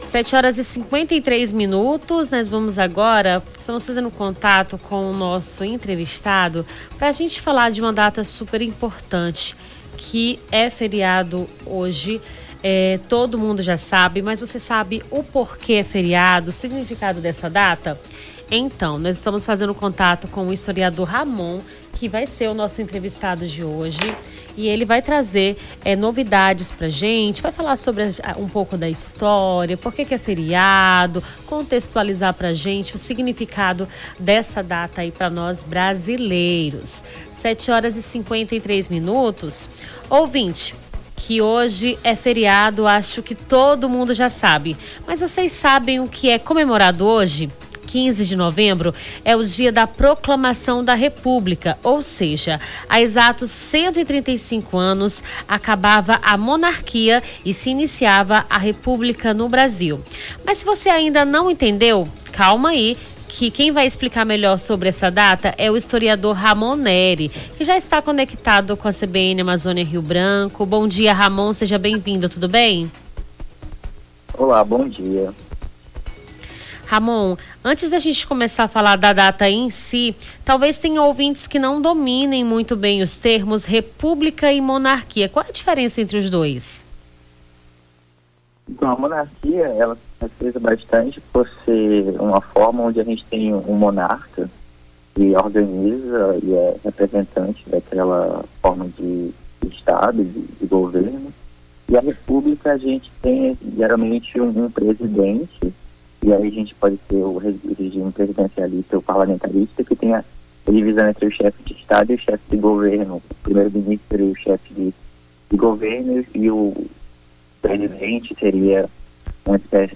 Nome do Artista - CENSURA- ENTREVISTA PROCLAMAÇÃO DA REPUBLICA (15-11-24).mp3